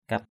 /kʌt/ (đg.) ghi nhận, làm dấu. kat thruh jalakaow kT E~H jl_k<| làm dấu nơi có tổ ong.